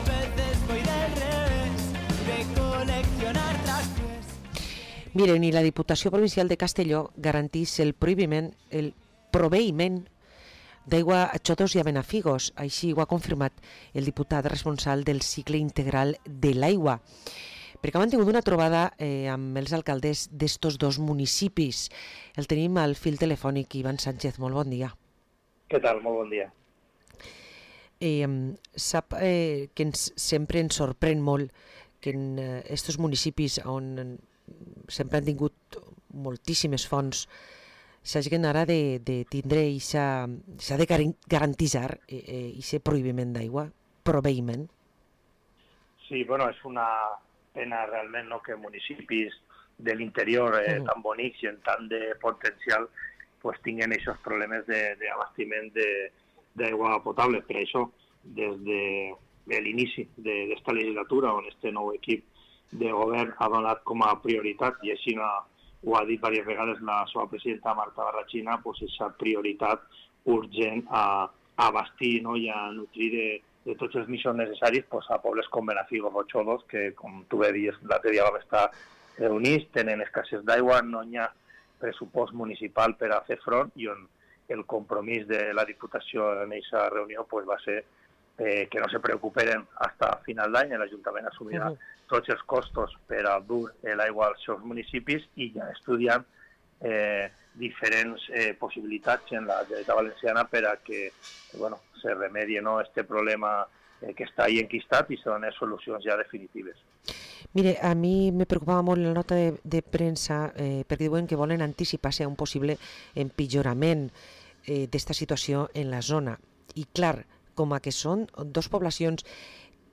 Parlem amb Iván Sánchez Cifre, Diputat responsable del Cicle integral de l’aigua